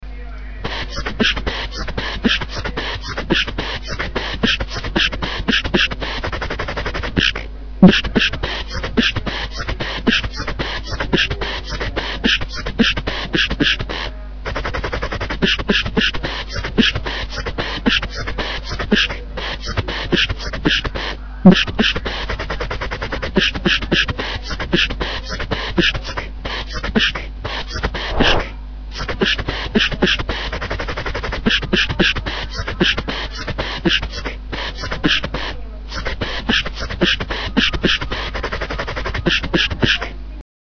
Битбокс от Google